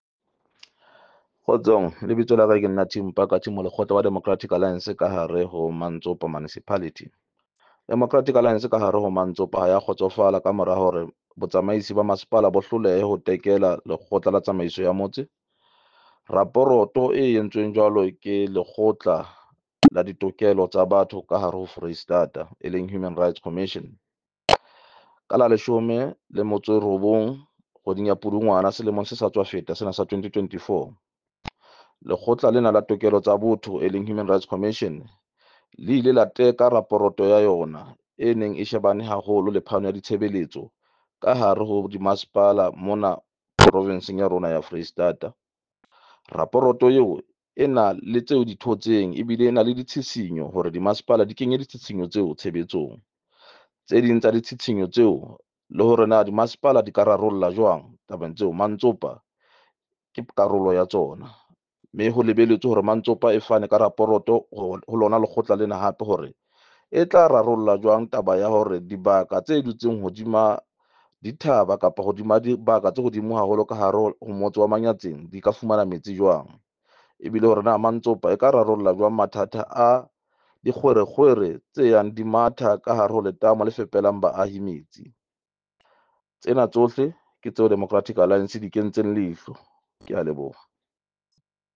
Sesotho soundbites by Cllr Tim Mpakathe.